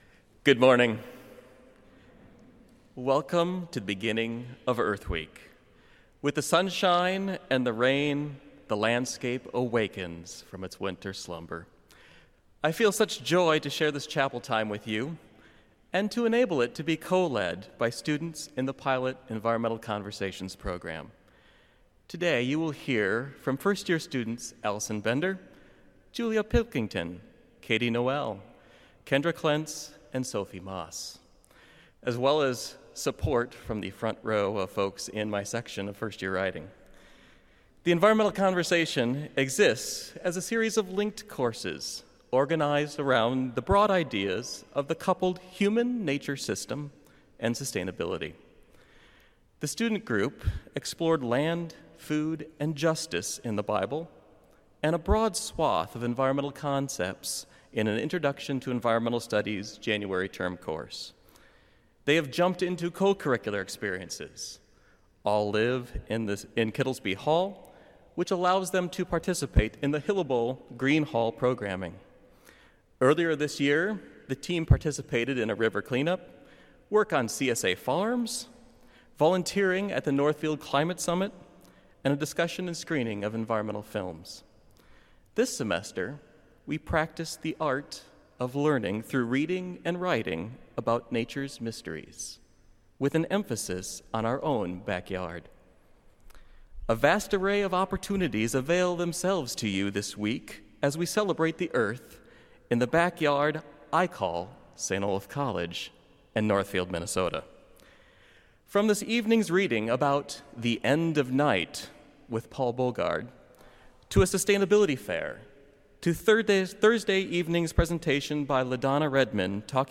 Students from the Environmental Conversations.
Boe Memorial Chapel